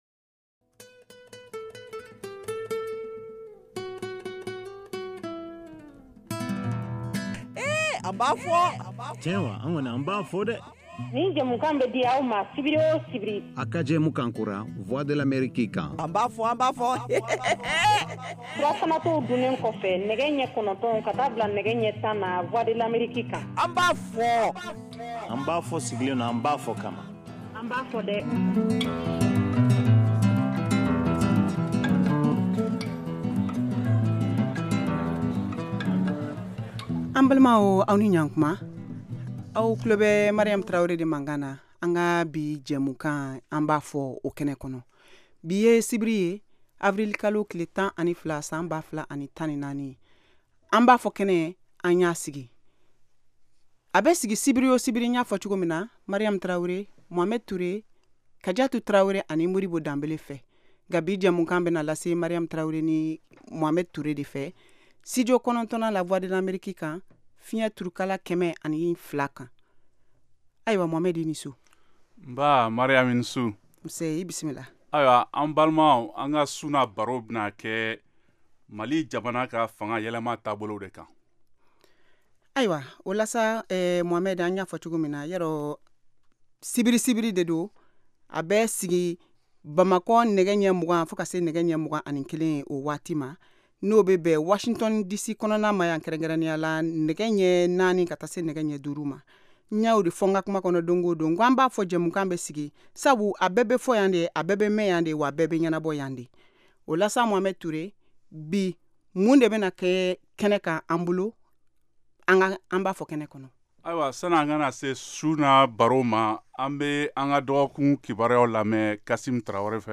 An ba fɔ! est une nouvelle émission interactive en Bambara diffusée en direct tous les samedis, de 20:00 à 21:00 T.U.